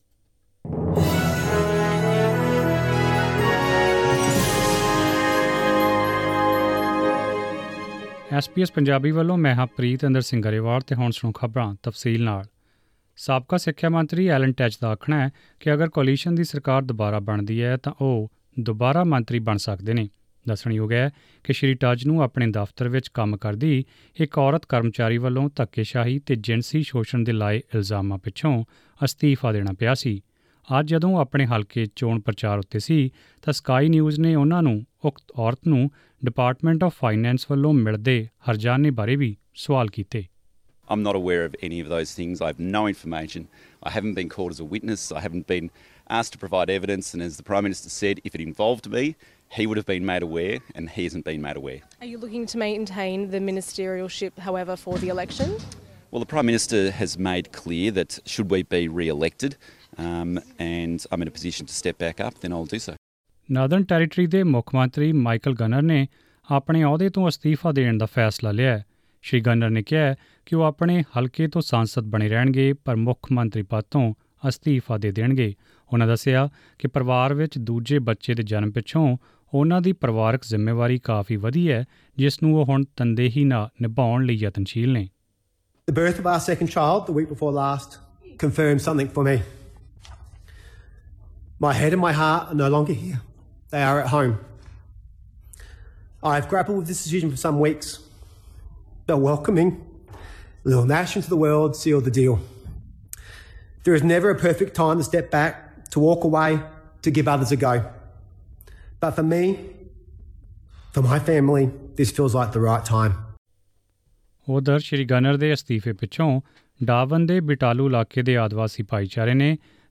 Presenting the major national and international news stories of today; sports, currency exchange rates and the weather forecast for tomorrow. Click on the audio button to listen to the news bulletin in Punjabi.